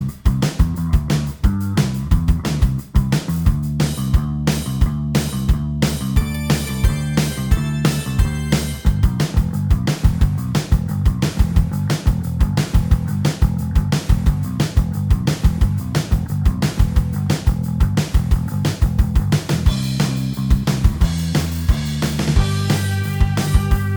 Minus All Guitars Except Rhythm Punk 3:46 Buy £1.50